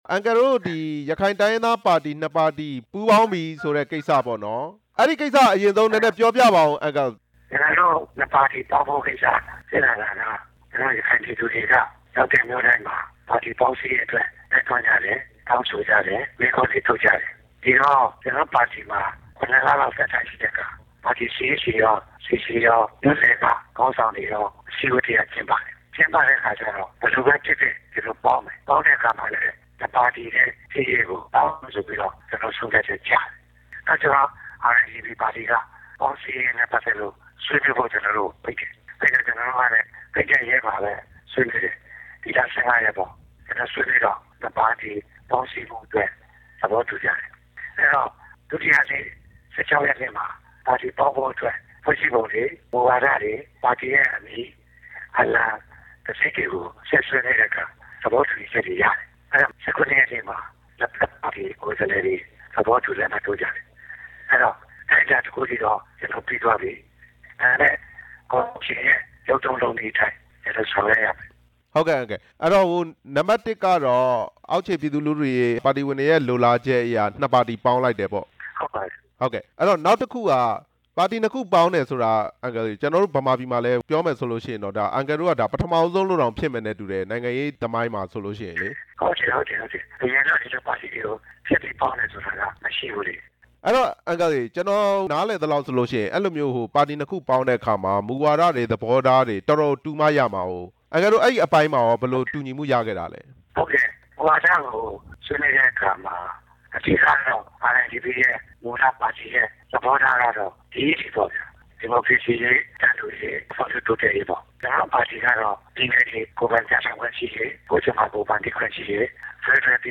ဦးအေးသာအောင်နှင့် မေးမြန်းချက်